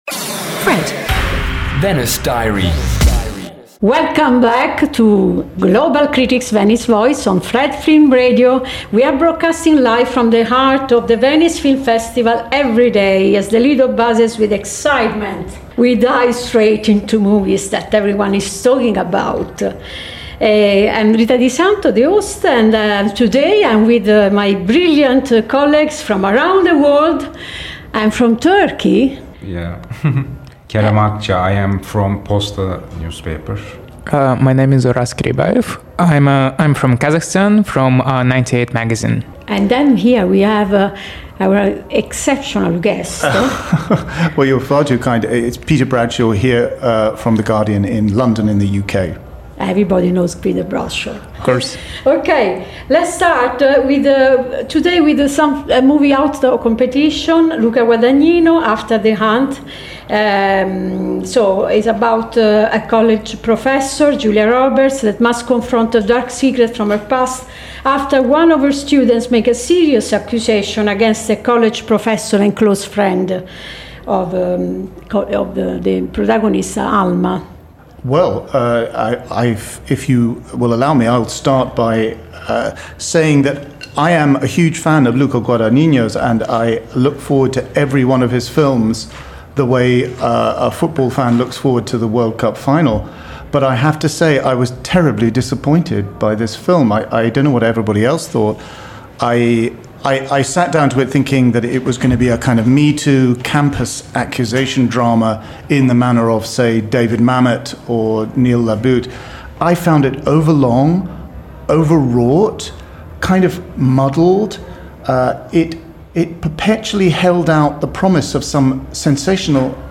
Each episode takes listeners inside the Festival with exclusive and thoughtful conversations with leading international film critics, and in-depth analysis of the year’s most anticipated films.